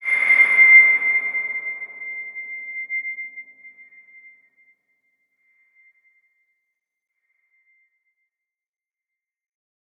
X_BasicBells-C5-mf.wav